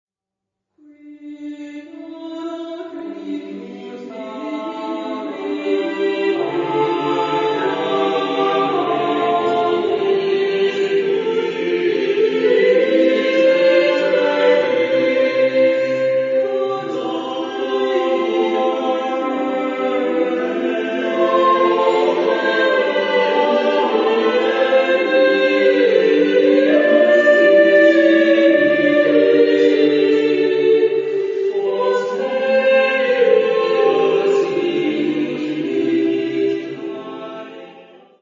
Epoque: 16th century
Genre-Style-Form: Motet ; Sacred
Type of Choir: SATB  (4 mixed voices )